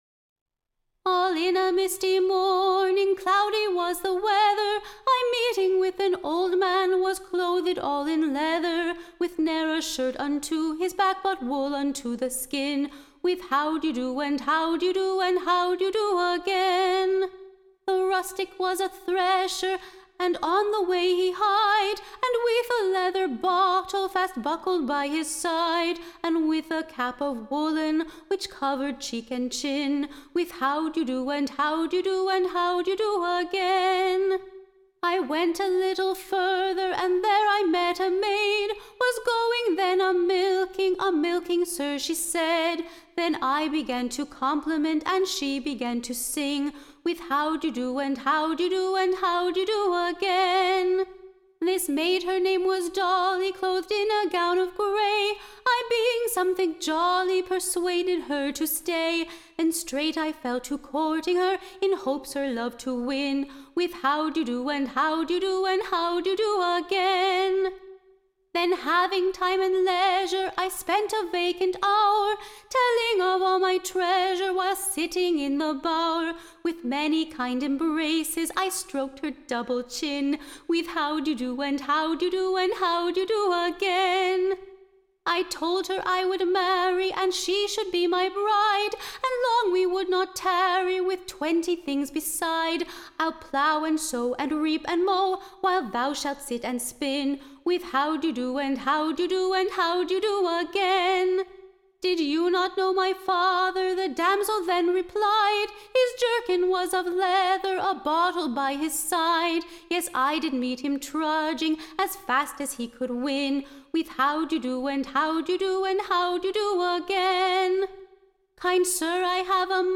Recording Information Ballad Title THE / Wiltshire Wedding / BETWIXT / Daniel Doo well and Doll the Dairy Maid.
Tune Imprint To an Excellent North-Country Tune.